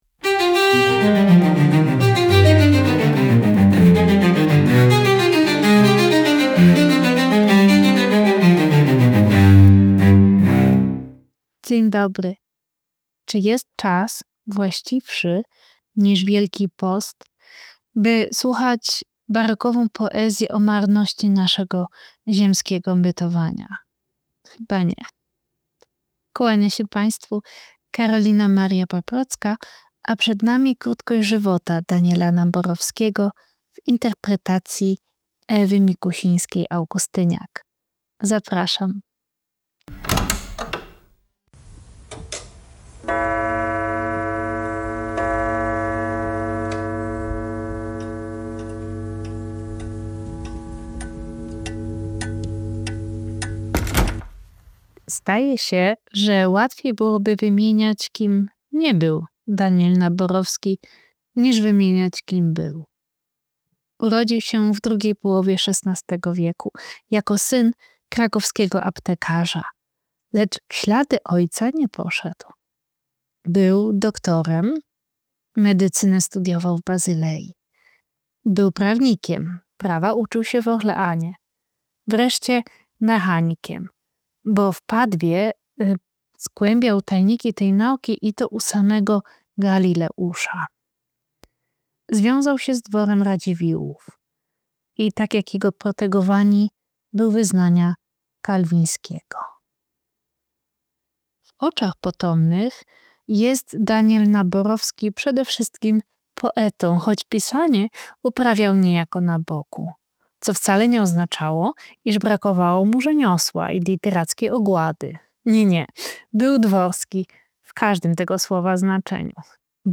Daniel Naborowski i barokowa "Krótkość żywota" - czytanie